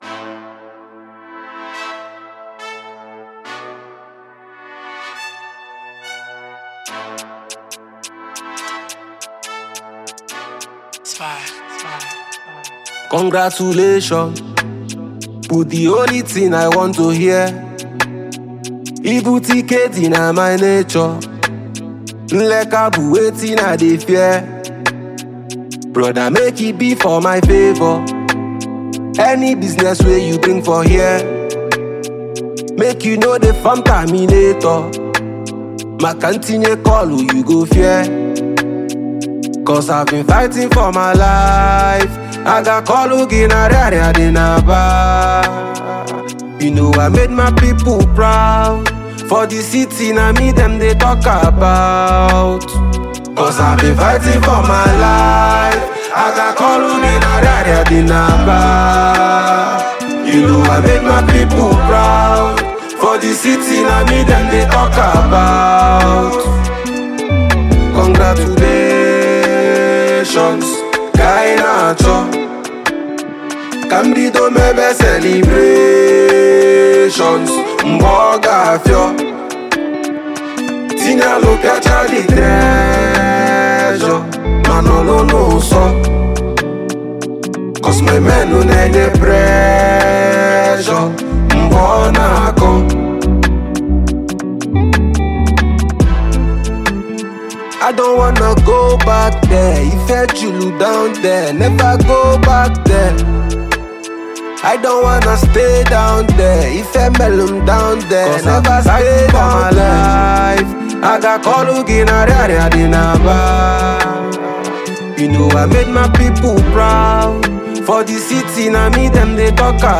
Indigenous rapper, singer and songwriter